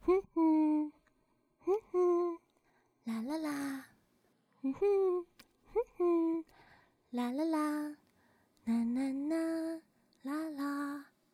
普通1.wav 0:00.00 0:11.35 普通1.wav WAV · 978 KB · 單聲道 (1ch) 下载文件 本站所有音效均采用 CC0 授权 ，可免费用于商业与个人项目，无需署名。
人声采集素材/人物休闲